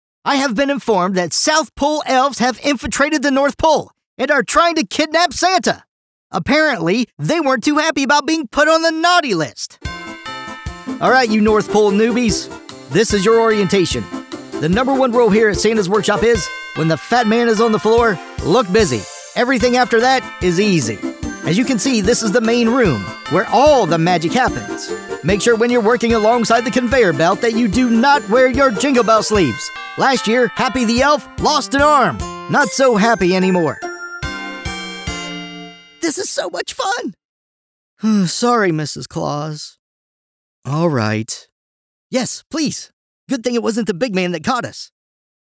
🎙 American Male Voice Over | Versatile, Professional & Broadcast-Ready
Santa’s Workshop Elf – Whimsical Voice Demo
Middle Aged
Sennheiser MKH 416 microphone — the gold standard for voice-over recording